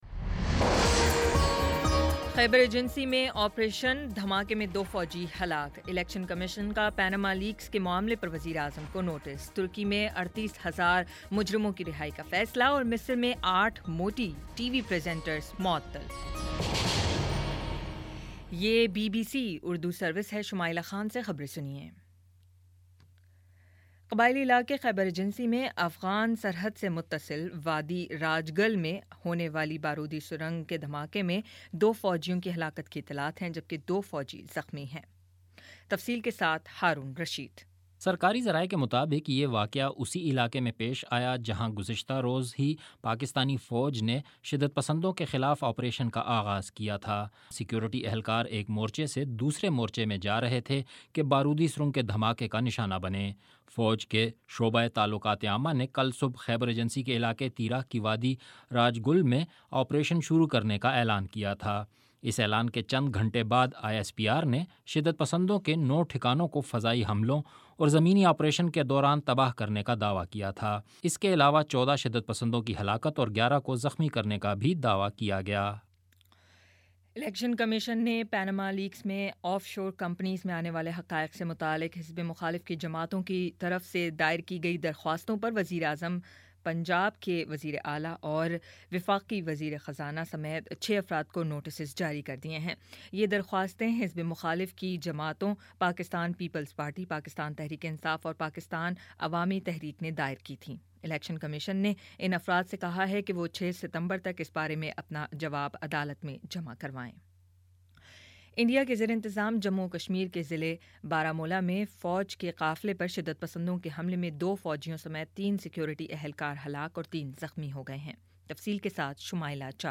اگست 17 : شام پانچ بجے کا نیوز بُلیٹن